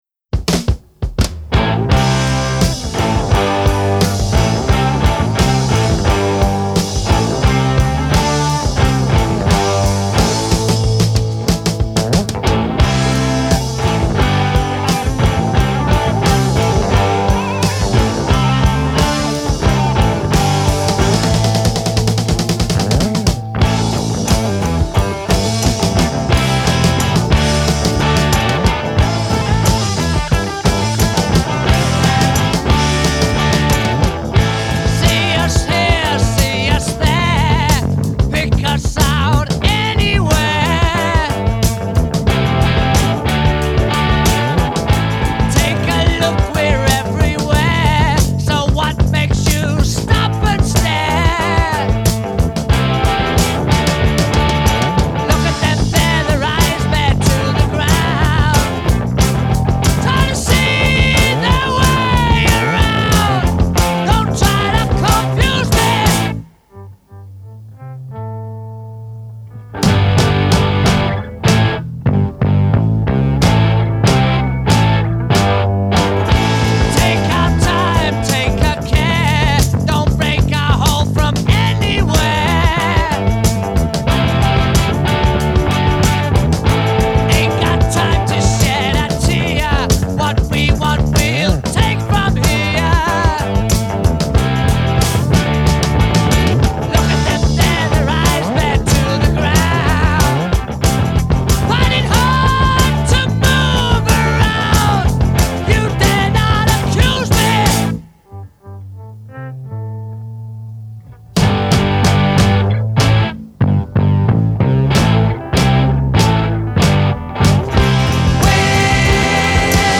heavy good time rock
distinctive half shouted vocal